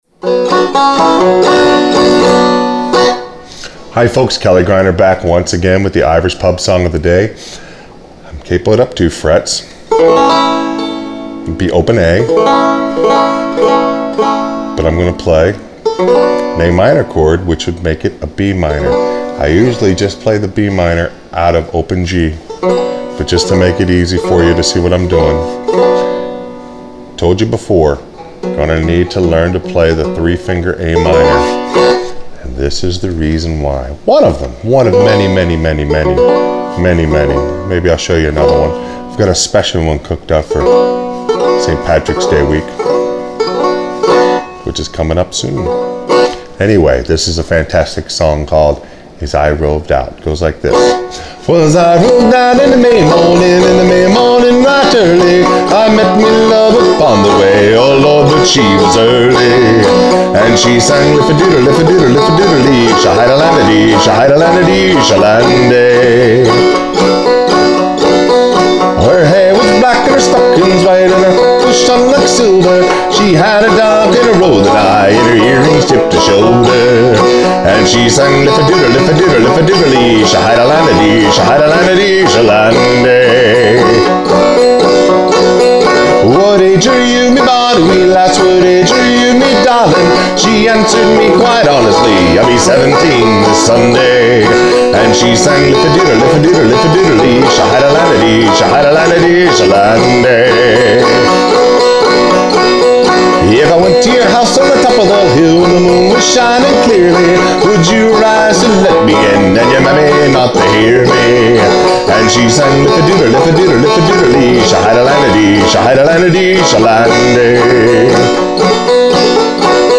It’s time for the 3 finger Am chord.